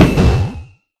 Sound / Minecraft / mob / enderdragon / hit3.ogg
hit3.ogg